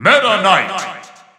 The announcer saying Meta Knight's name in English and Japanese releases of Super Smash Bros. 4 and Super Smash Bros. Ultimate.
Meta_Knight_English_Announcer_SSB4-SSBU.wav